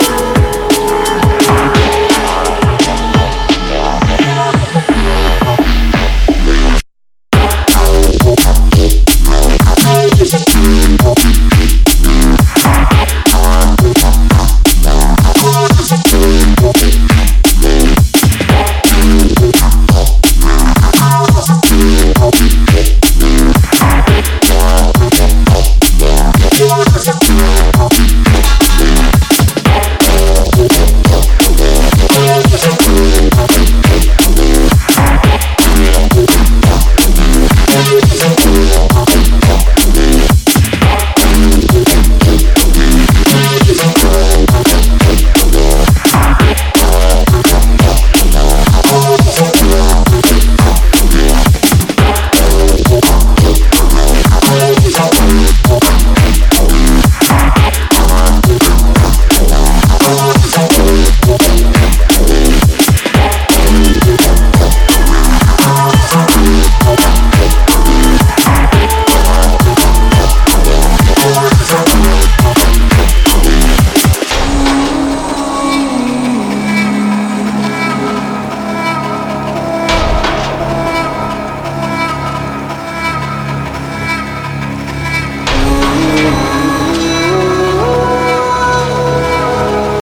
Drum and Bass / Jungle